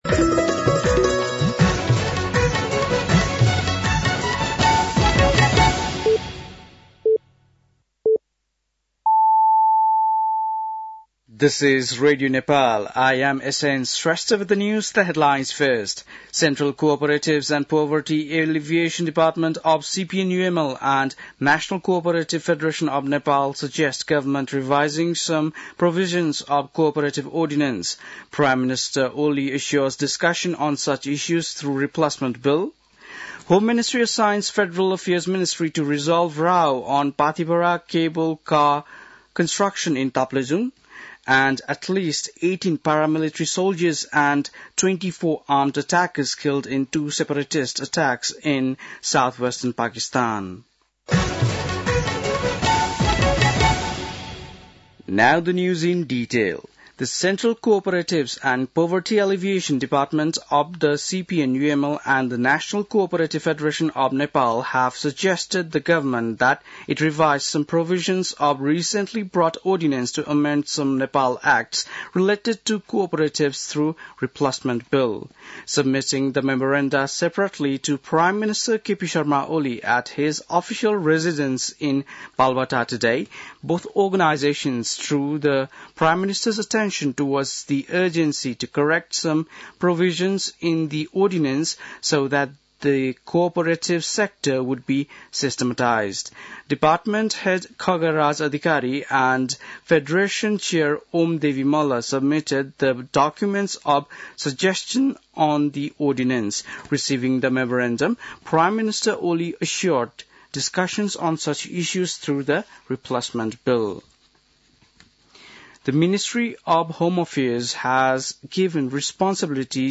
बेलुकी ८ बजेको अङ्ग्रेजी समाचार : २० माघ , २०८१